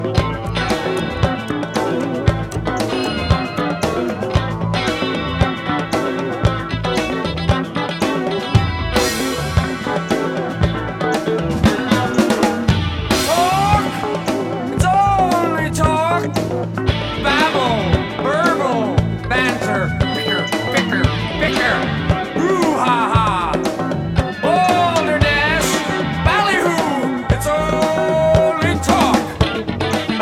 Rock psychédélique, rock progressif, rock symphonique
Enregistrement remasterisé